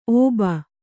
♪ ōba